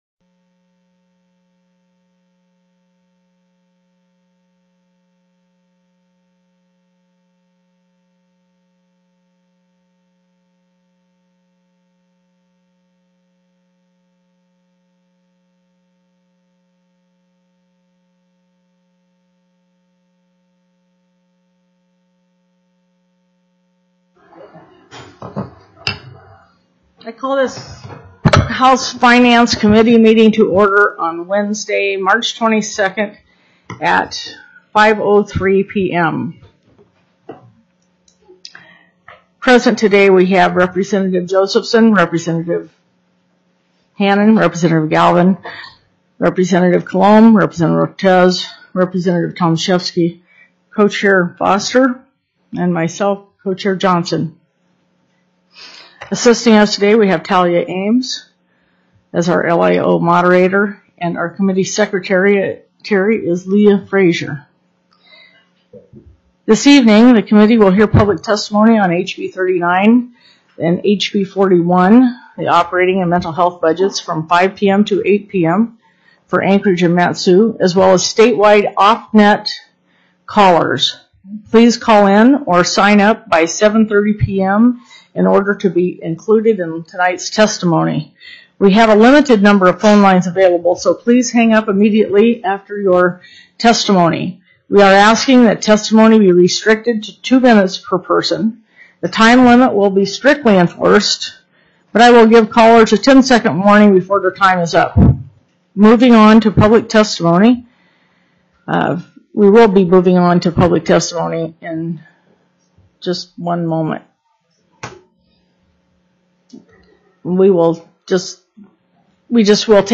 The audio recordings are captured by our records offices as the official record of the meeting and will have more accurate timestamps.
Public Testimony: Anchorage, Mat-su, and Offnets